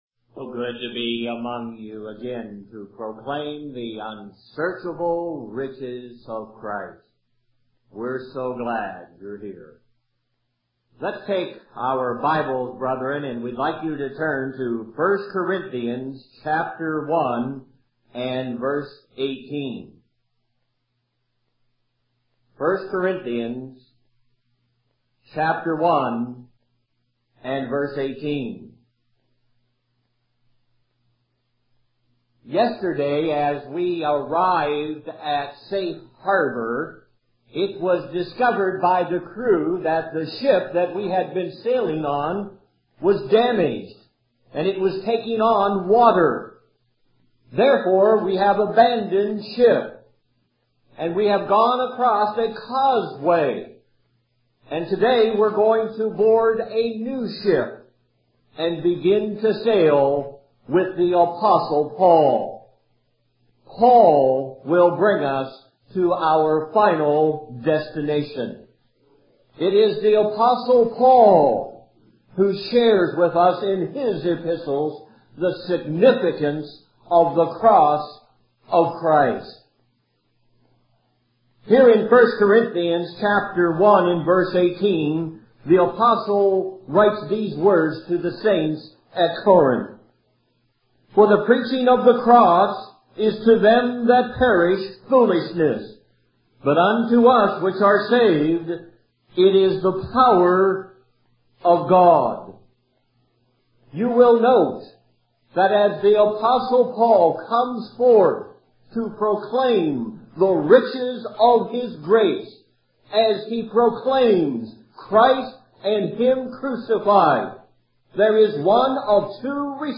You're listening to Lesson 3 from the sermon series "Christ and Him Crucified"